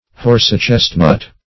Horse-chestnut \Horse`-chest"nut\, Horsechestnut
\Horse`chest"nut\, n.